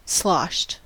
Ääntäminen
Ääntäminen US UK : IPA : /ˈslɒʃəd/ Haettu sana löytyi näillä lähdekielillä: englanti Käännöksiä ei löytynyt valitulle kohdekielelle.